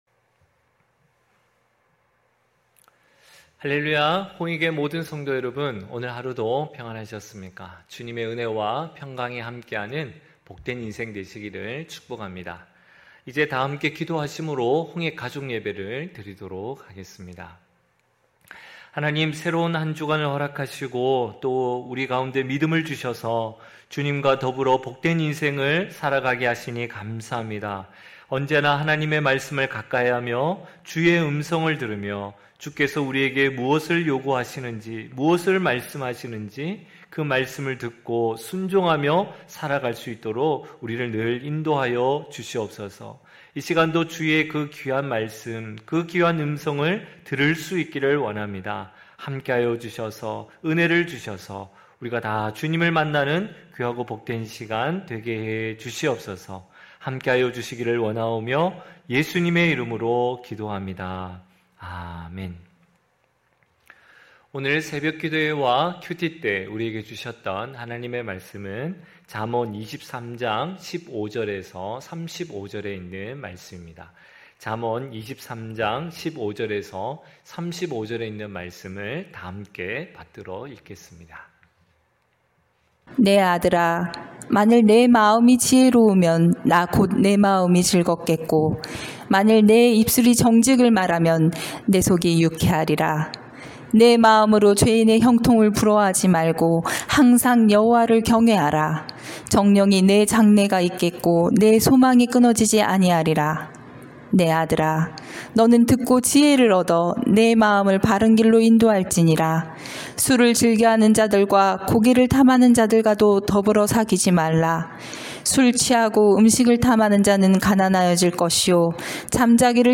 9시홍익가족예배(6월14일).mp3